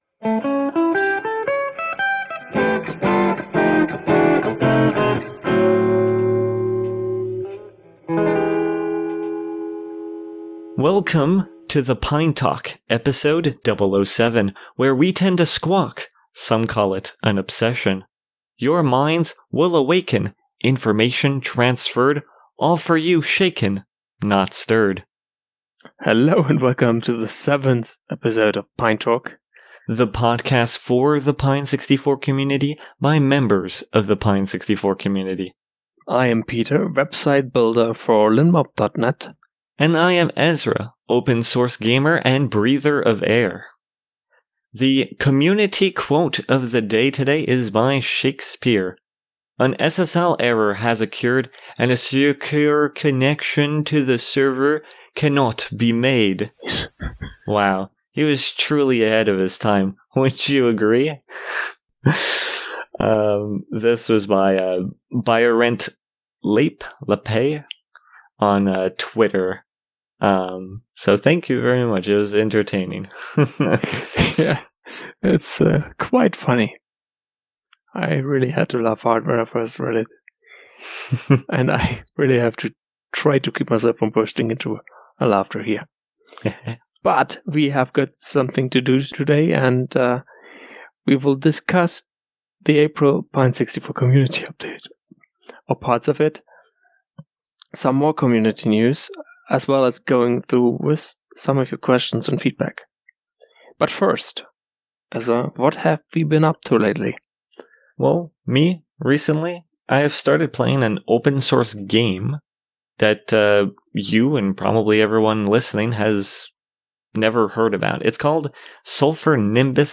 Remember: This is a community podcast, so please leave feedback on what we should do better, get your suggestion’s in and feel free to ask questions!